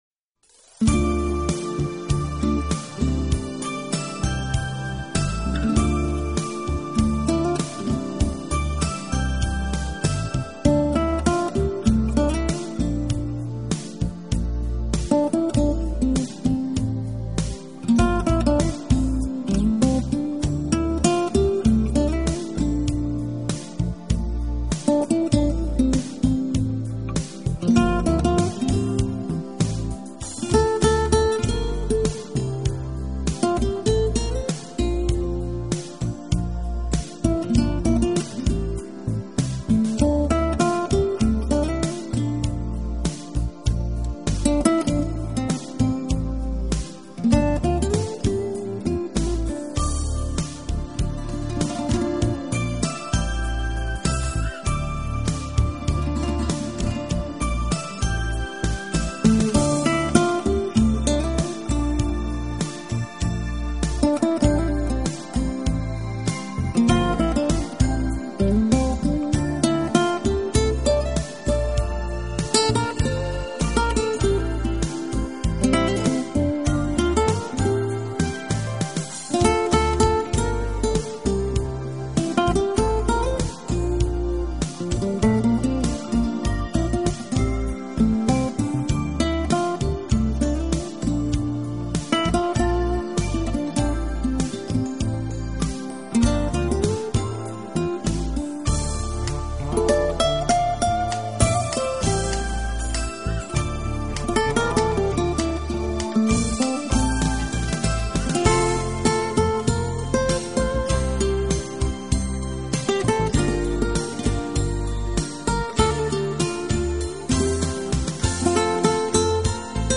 音乐类型：Jazz
guitar, keyboards
flute, saxophone, oboe
drums, percussion
background vocals
Recorded at Moving Hands Studio, Los Angeles, California